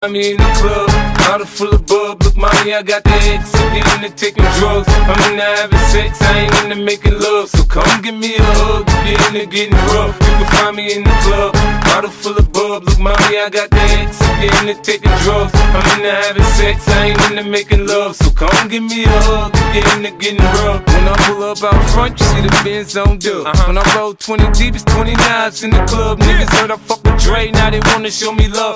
Categoría Pop